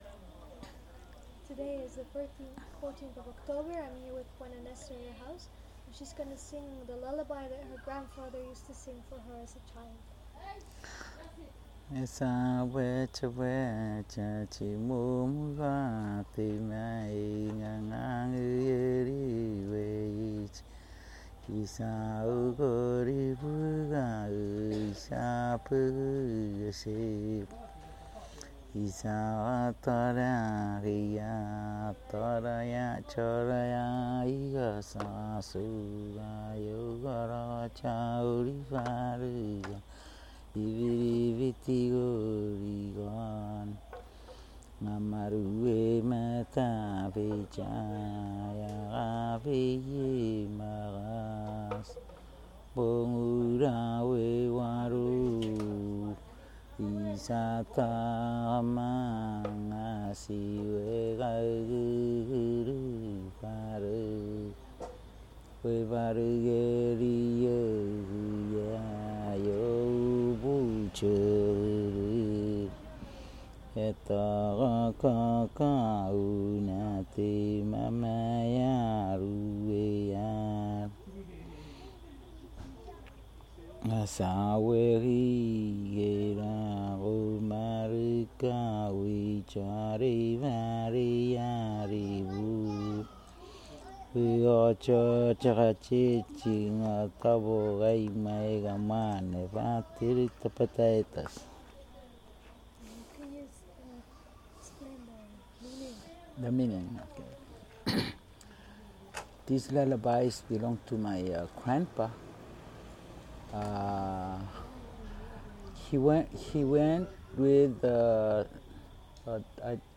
dc.descriptionLullaby
dc.description.regionEchang, Koror, Palau
dc.formatdigital wav file recorded at 44.1 kHz/16 bit on Zoom H2N